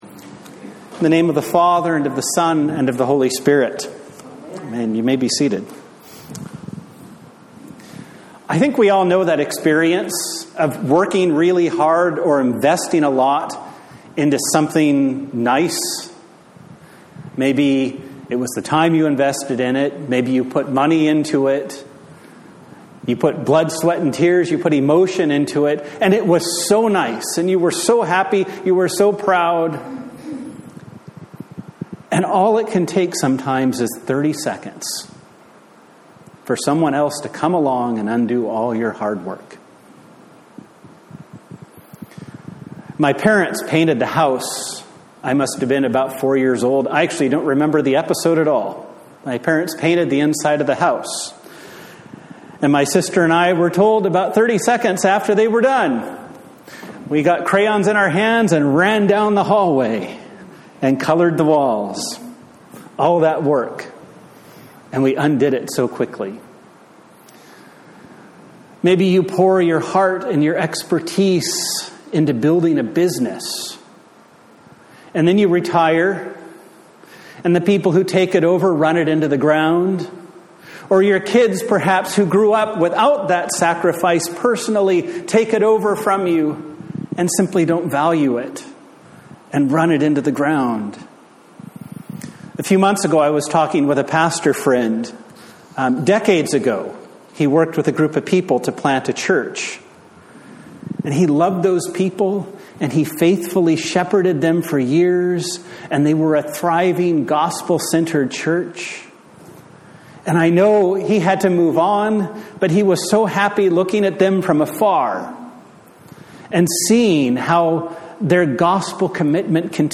Galatians 6:11-18 Service Type: Sunday Morning The Fifteenth Sunday after Trinity